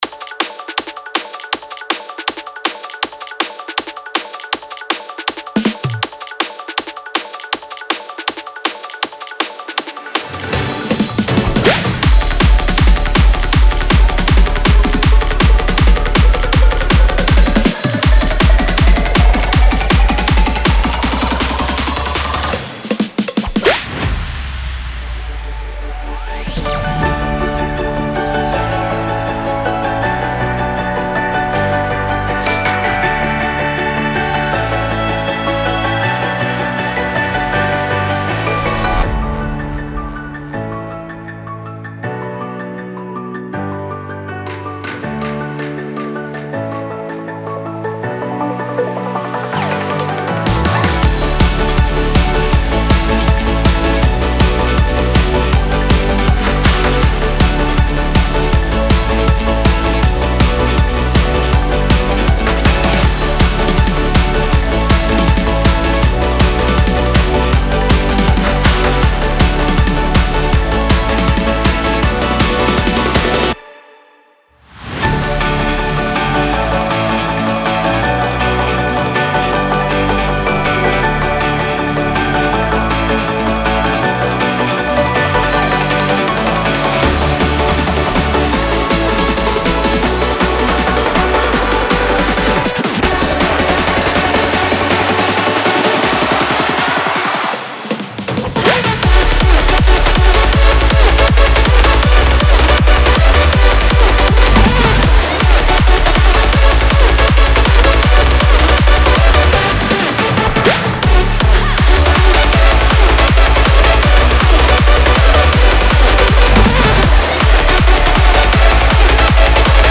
Download Instrumental Version